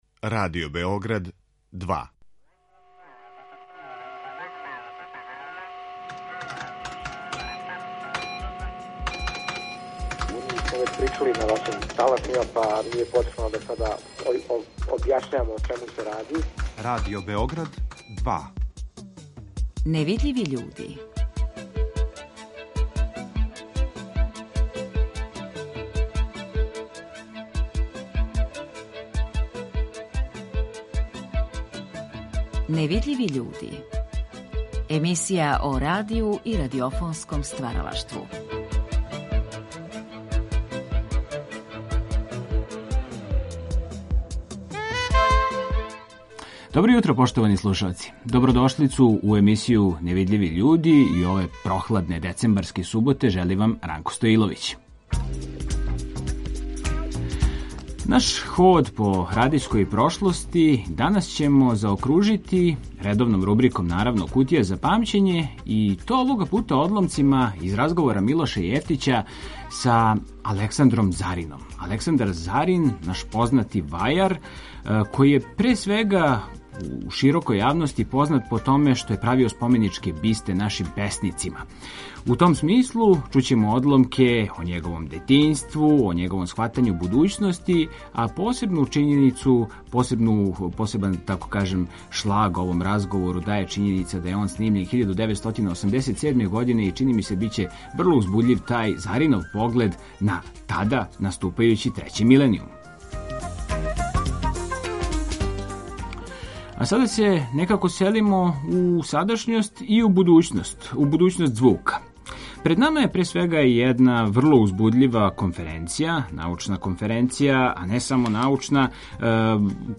Са гостима разговарамо о програму овогодишње конференције, али и о актуелностима и тренутним глобалним изазовима на пољу дизајна звука, о раду са студентима, па и о месту радија у контексту експанзије свих осталих аудио-визуелних платформи и нових медија.